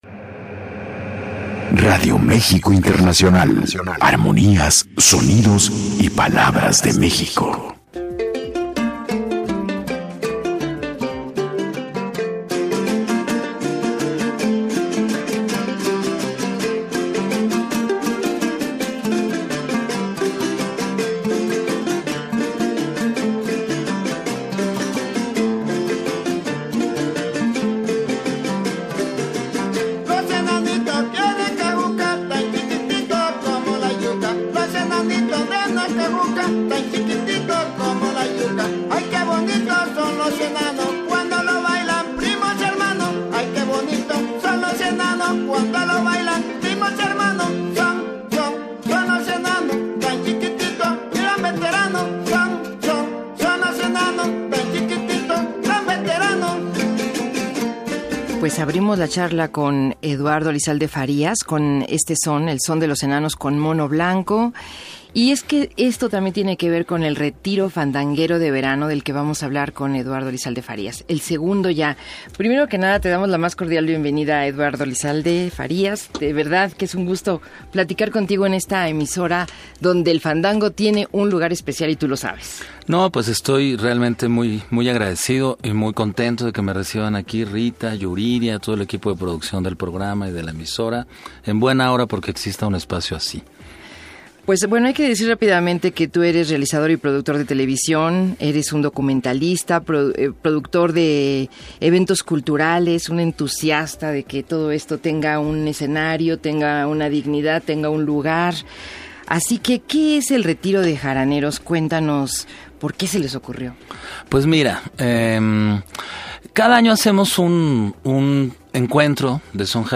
entrevista_retiro_fandanguero.mp3